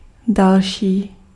Ääntäminen
Vaihtoehtoiset kirjoitusmuodot (rikkinäinen englanti) furder Synonyymit accelerate other farther moreover in addition Ääntäminen US : IPA : [ˈfɝ.ðɚ] Tuntematon aksentti: IPA : /fɜː(r)ðə(r)/ IPA : /ˈfɜː.ðə/